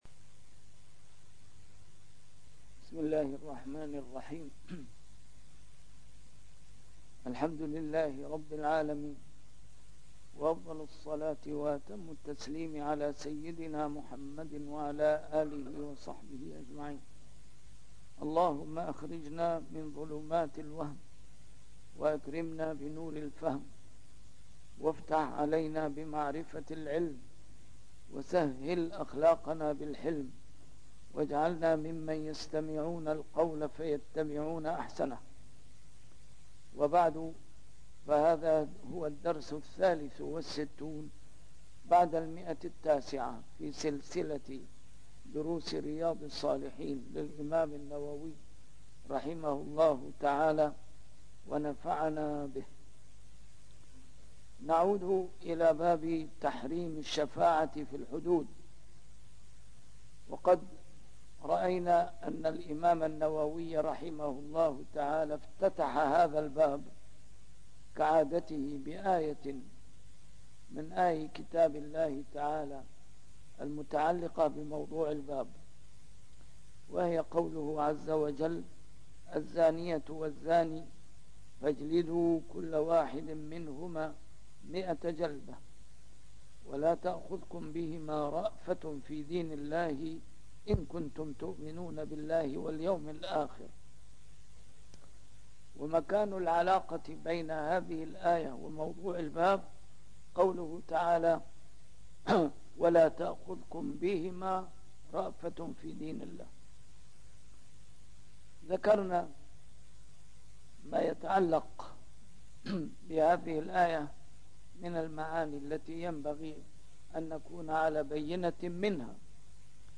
A MARTYR SCHOLAR: IMAM MUHAMMAD SAEED RAMADAN AL-BOUTI - الدروس العلمية - شرح كتاب رياض الصالحين - 963- شرح رياض الصالحين: تحريم الشفاعة في الحدود